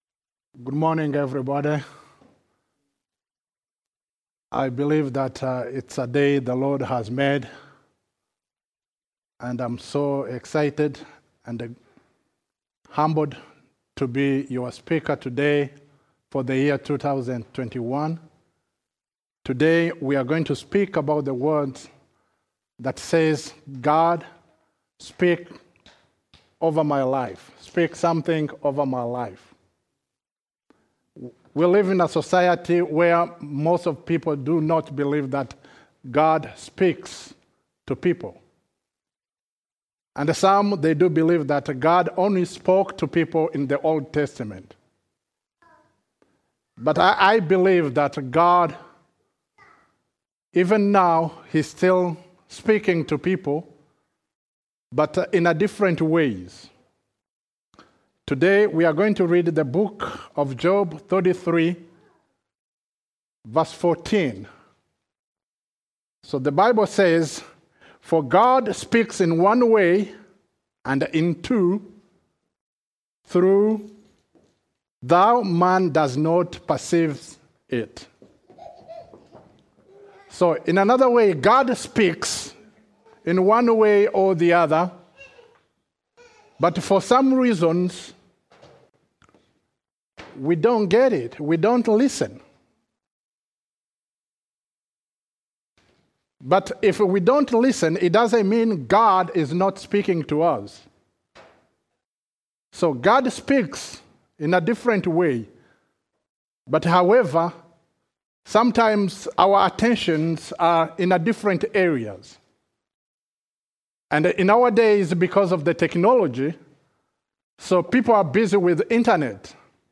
We will also partake in communion.